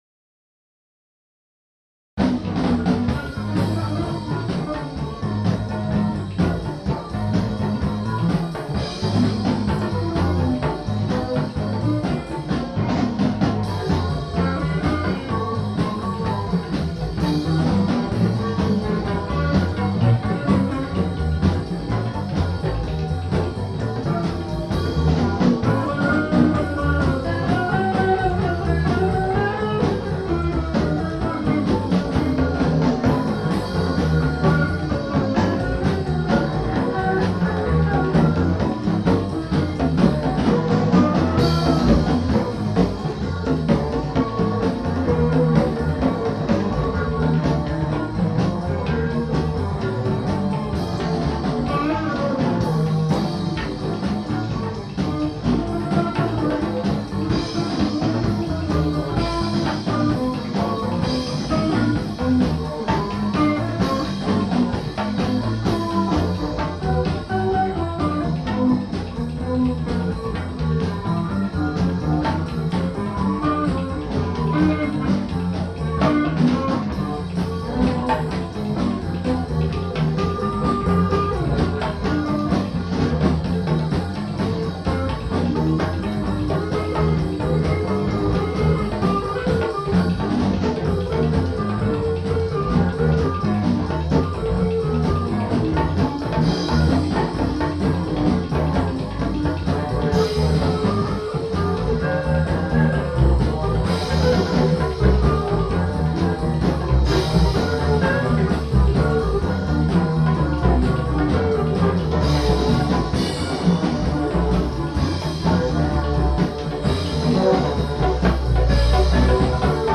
e-bass
guitar
voc, perc.
perc., sax, flute
tuba, microsynth
drums, perc.